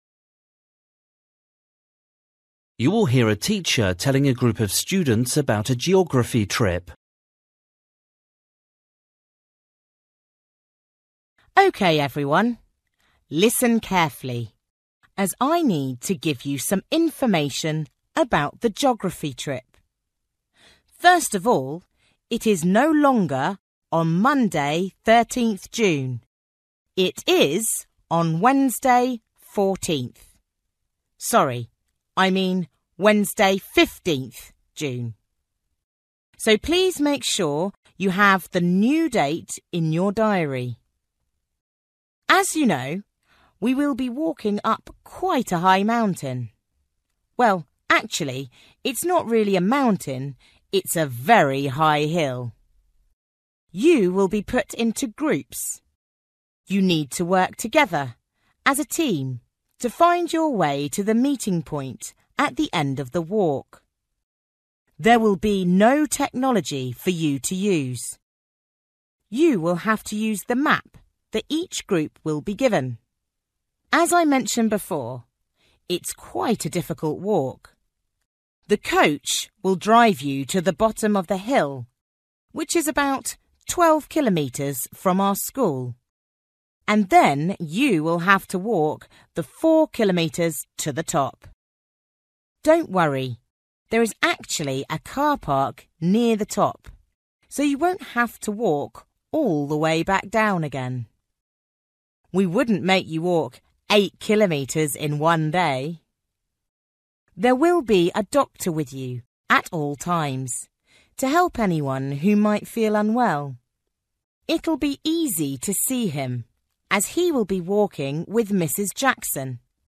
You will hear a teacher telling a group of students about a geography trip.